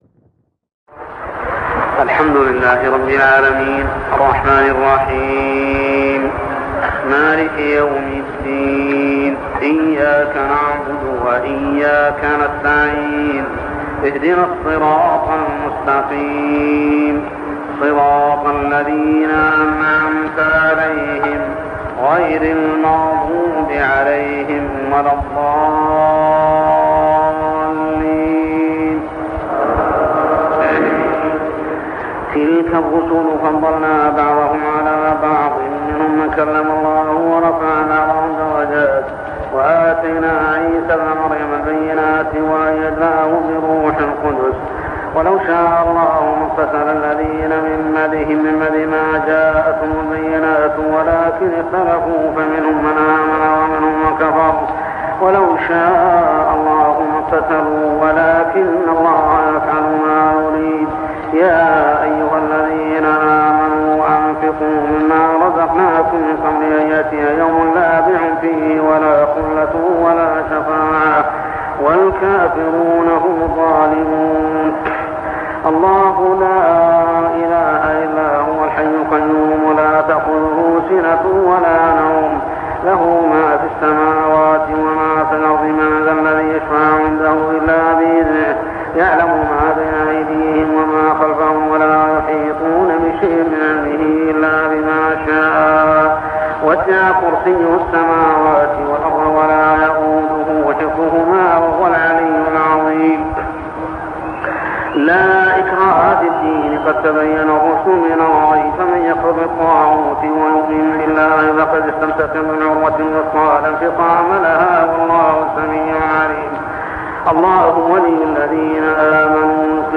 صلاة التراويح عام 1401هـ سورتي البقرة 253-286 و آل عمران 1-14 | Tarawih prayer Surah Al-Baqarah and Al-Imran > تراويح الحرم المكي عام 1401 🕋 > التراويح - تلاوات الحرمين